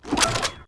ADPCM (ton_bb_ima.wav) bringt es auch auf ein Viertel bei deutlich besserem Klang.